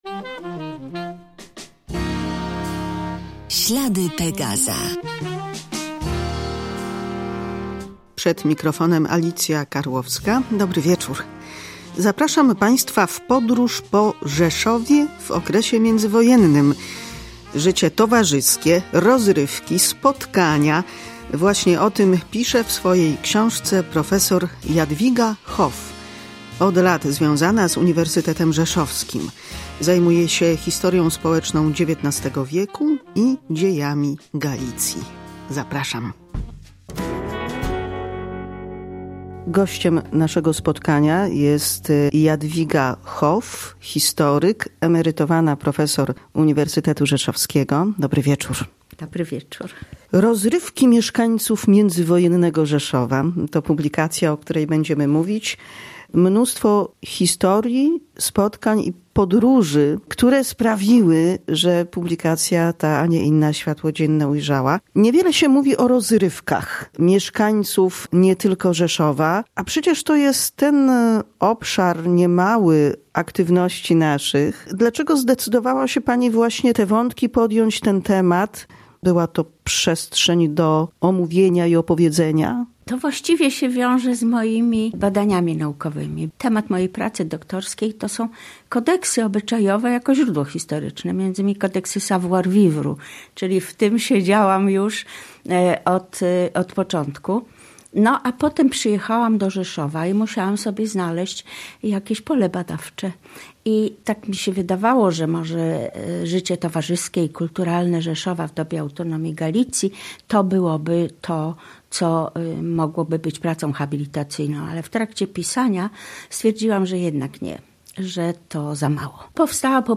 Ślady Pegaza • Życie teatralno-muzyczne, aktywność w rozmaitych stowarzyszeniach, potańcówki, bale. Jak i gdzie bawili się rzeszowianie w okresie międzywojnia? Posłuchajmy rozmowy